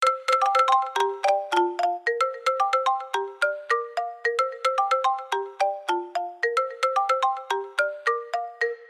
lingsheng.mp3